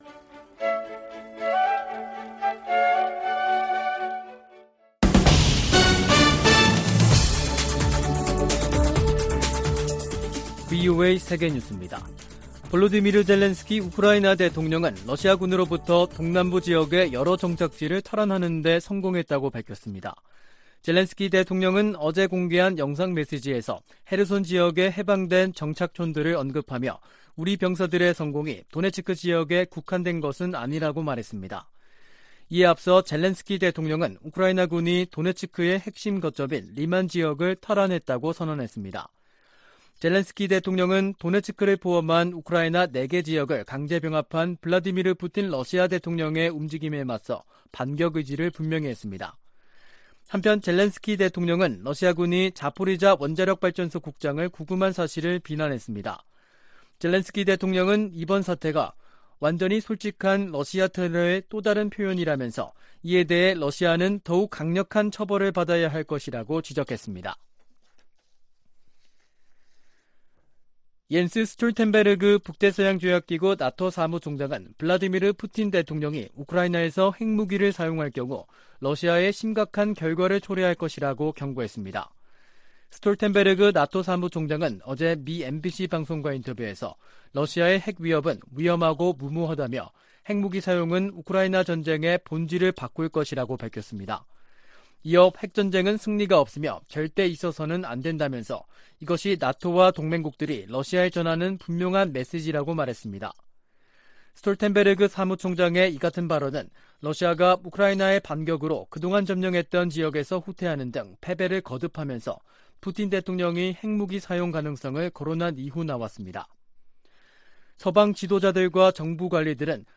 VOA 한국어 간판 뉴스 프로그램 '뉴스 투데이', 2022년 10월 3일 2부 방송입니다. 북한이 지난 1일 동해상으로 탄도미사일(SRBM) 2발을 발사해 지난 달 25일 이후 총 7발의 미사일을 발사했습니다. 미 국무부는 잇따른 탄도미사일 발사로 안정을 흔드는 북한의 무기 역량을 제한하겠다는 의지를 나타냈습니다. 한국 탈북자 그룹이 또 신종 코로나바이러스 감염증 의약품 등을 매단 대형 풍선을 북한으로 보낸 것으로 알려졌습니다.